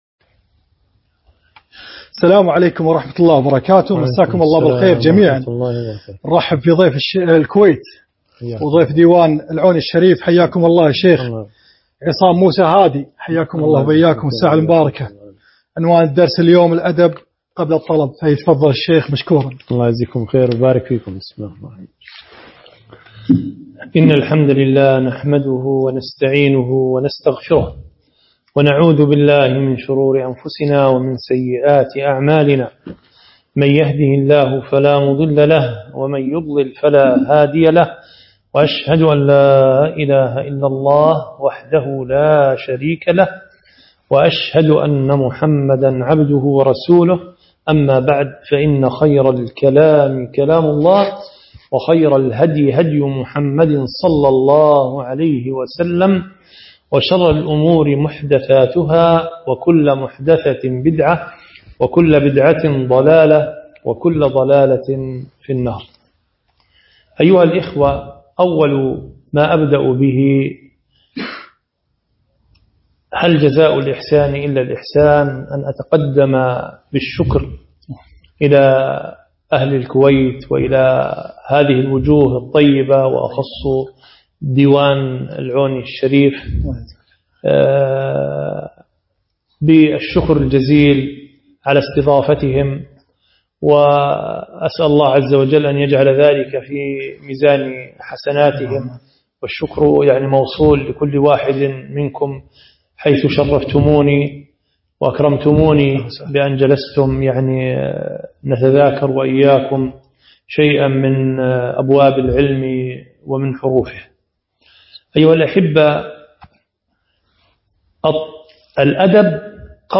محاضرة - الأدب قبل الطلب